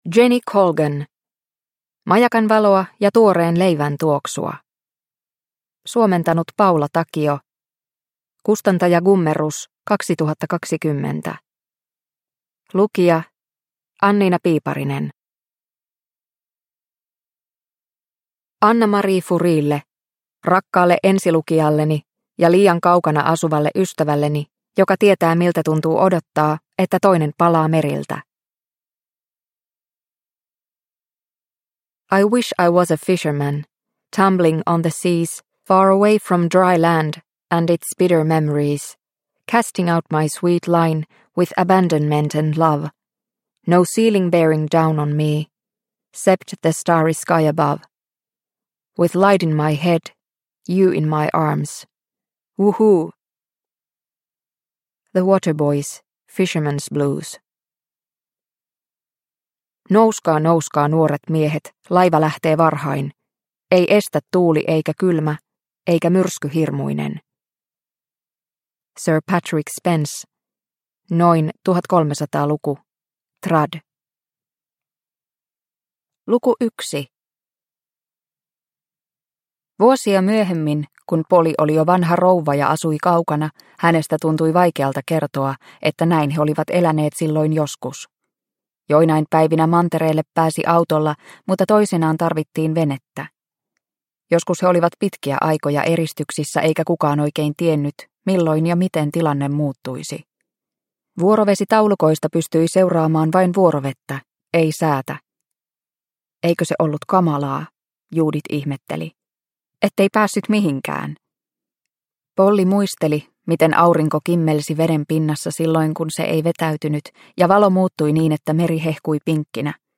Majakanvaloa ja tuoreen leivän tuoksua – Ljudbok – Laddas ner